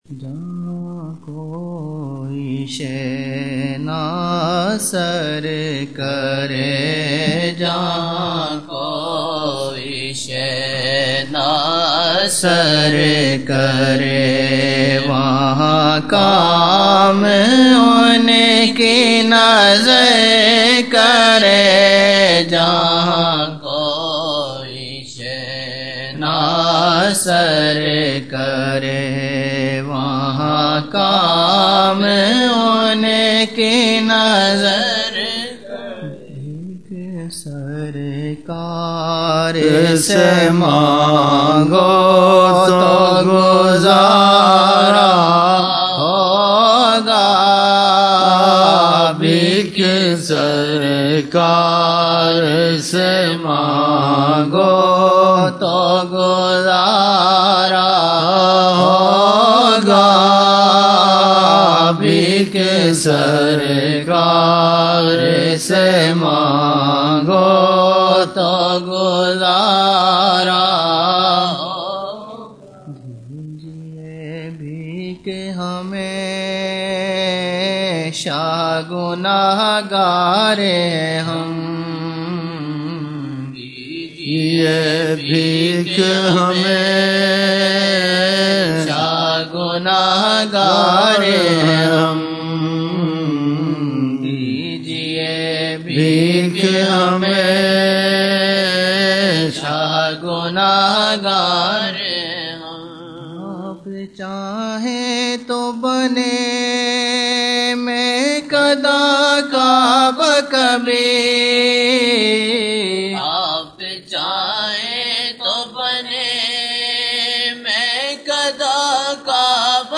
Old Naat Shareef